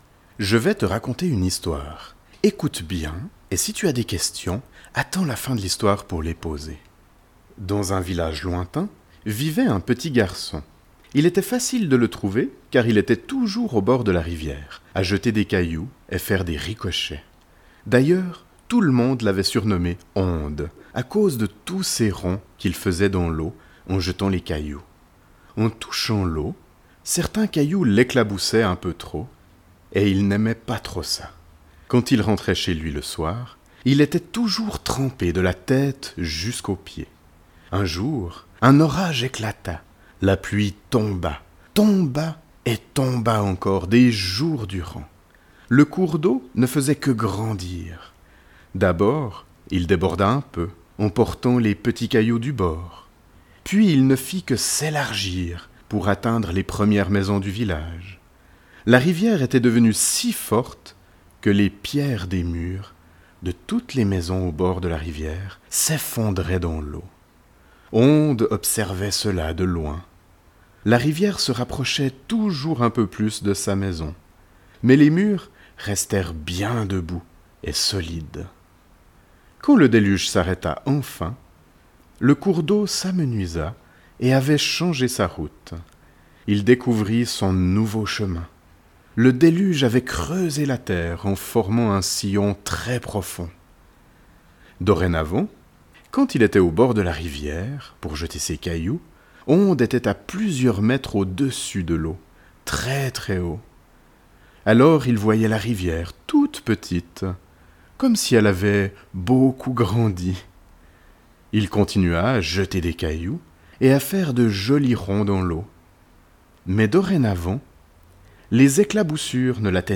Ces audios sont des contes métaphoriques à destination des enfants.